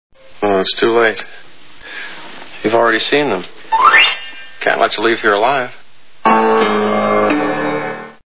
The Fly Movie Sound Bites